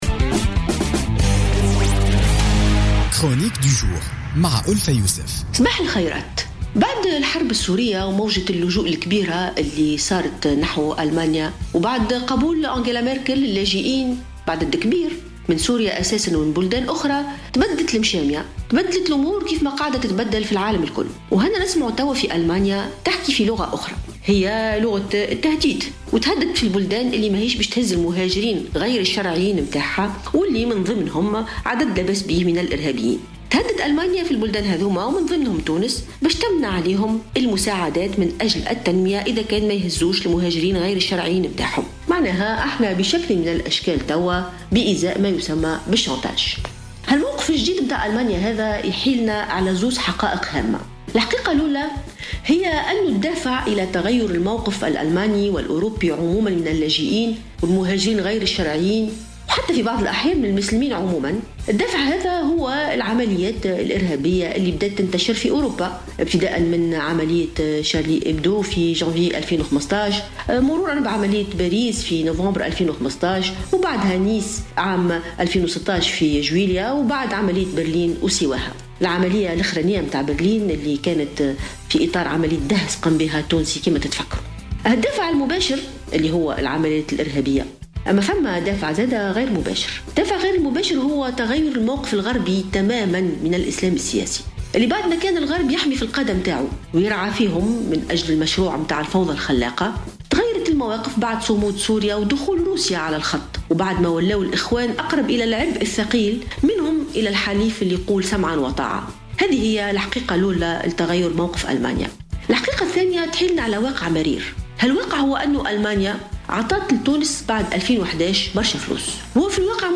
تساءلت الكاتبة ألفة يوسف في افتتاحيتها لـ "الجوهرة اف أم" اليوم الخميس عن مصير التمويلات الأجنبية لتونس ومآلها بعد الثورة.